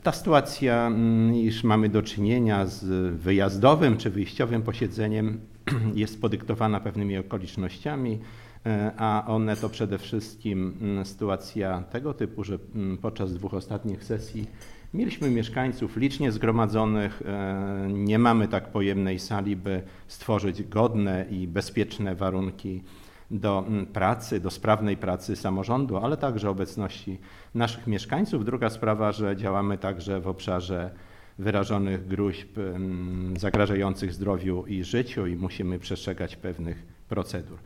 – Działamy też w obszarze wyrażonych gróźb, zagrażających zdrowiu i życiu przez co musimy przestrzegać pewnych procedur – poinformował Zdzisław Przełomiec, przewodniczący rady Miasta Suwałki.